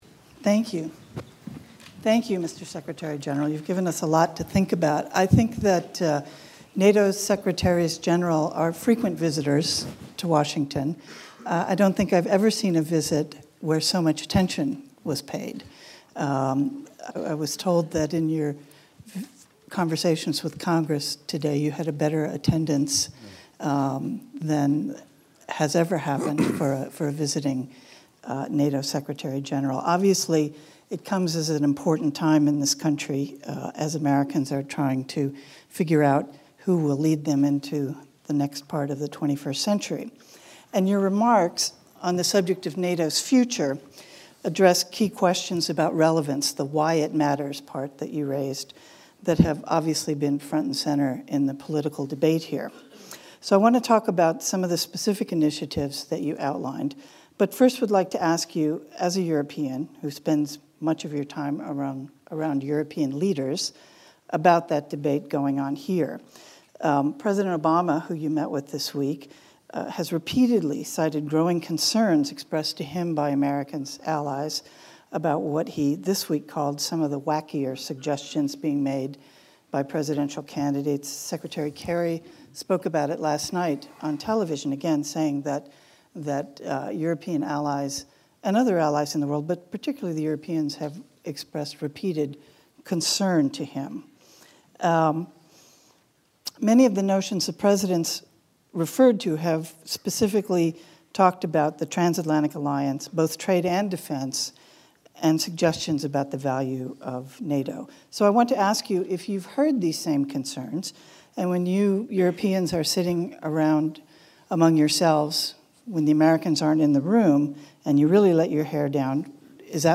Speech by NATO Secretary General Jens Stoltenberg to the Atlantic Council, Washington, D.C.